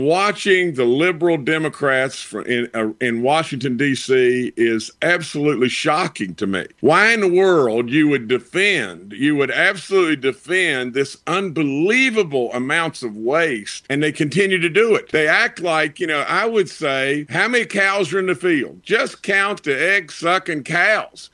West Virginia U.S. Senator Jim Justice spoke with FOX News regarding pushback around trimming back government.